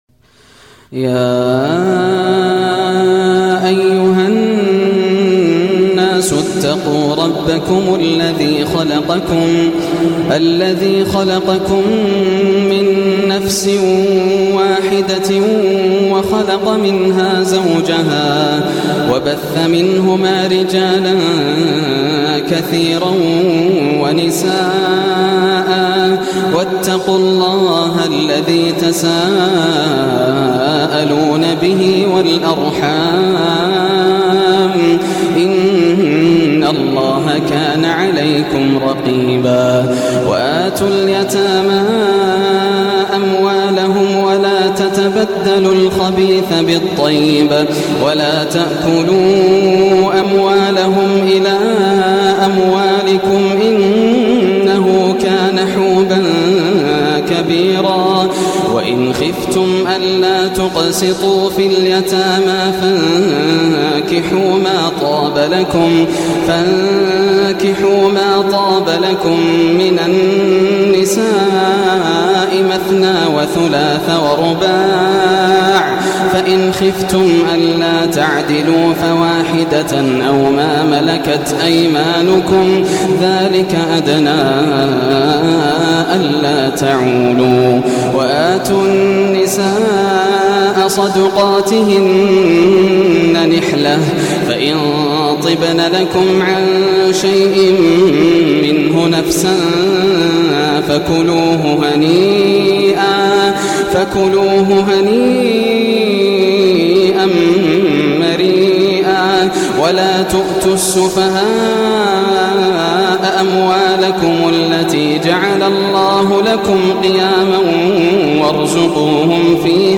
سورة النساء > السور المكتملة > رمضان 1425 هـ > التراويح - تلاوات ياسر الدوسري